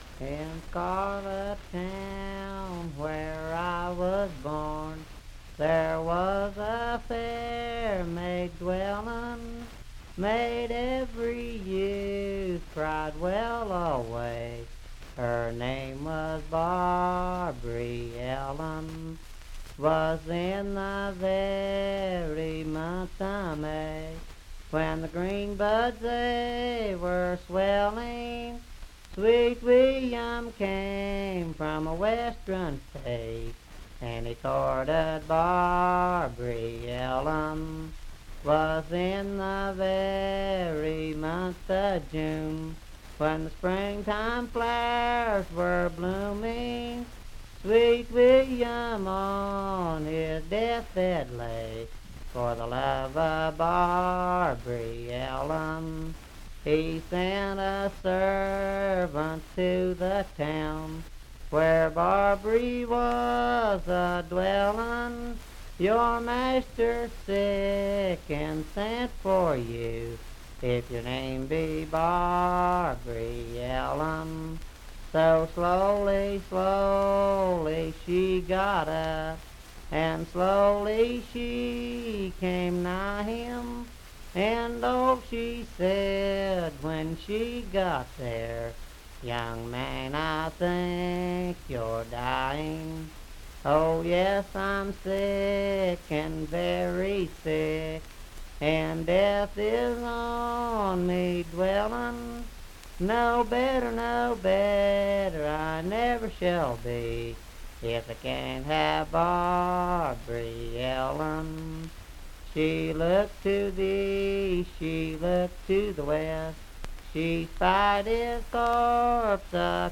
Unaccompanied vocal music
Verse-refrain 9(4).
Voice (sung)